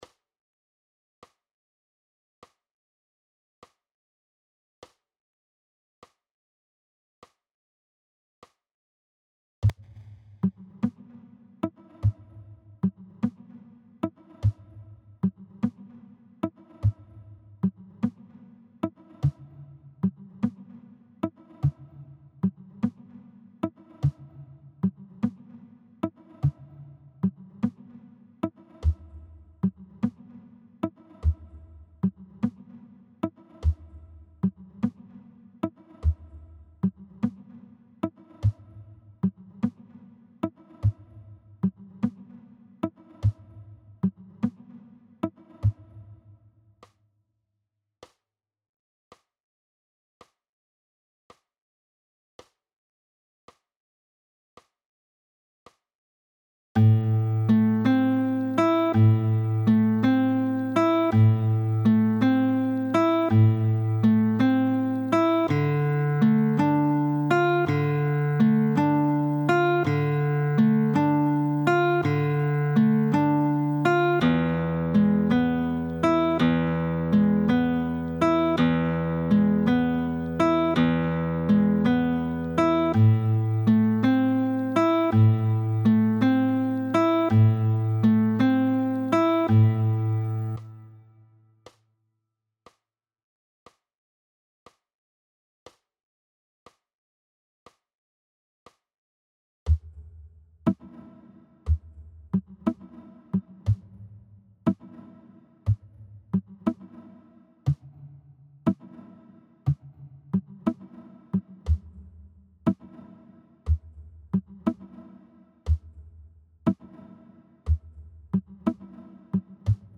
II. Shuffle … Akkordbegleitung: PDF
+ Audio 50 – 80 bpm:
II.-Shuffle-.-Akkordbegleitungen.mp3